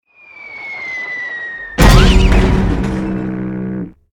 AA_drop_boat_miss.ogg